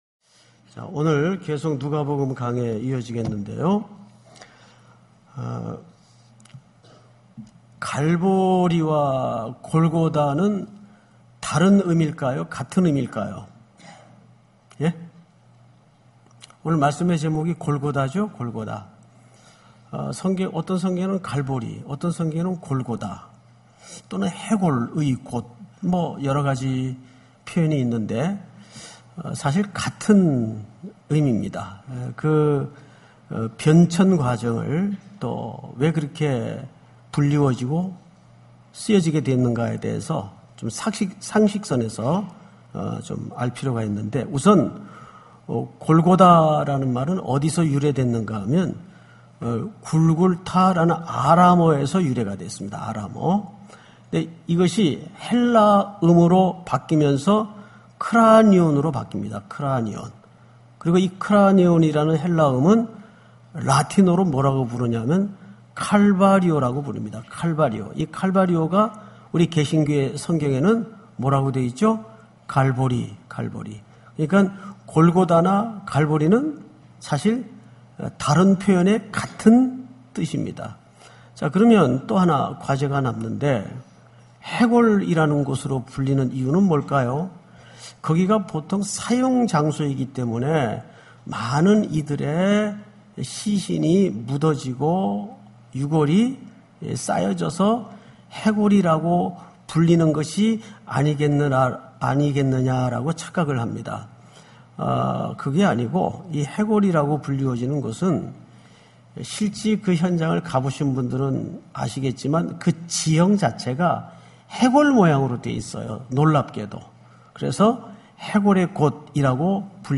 예배 주일저녁예배